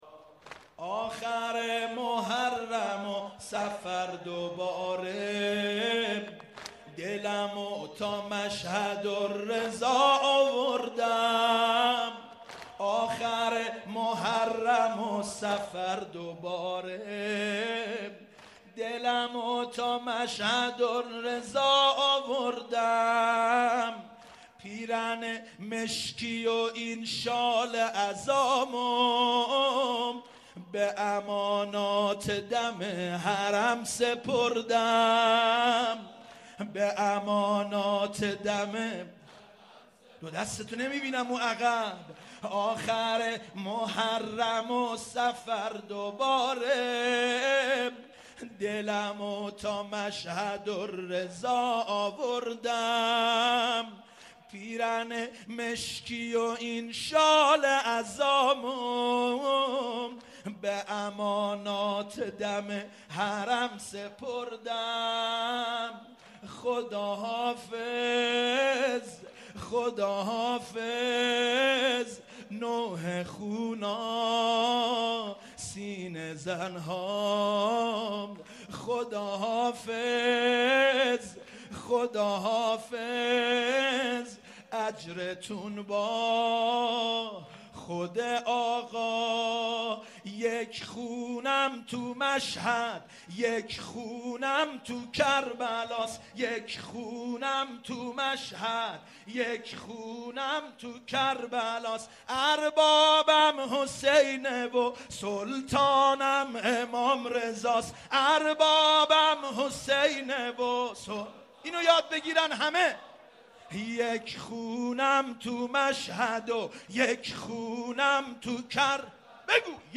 ذکر توسل و عرض اردت به محضر امام غریب، امام رضا(ع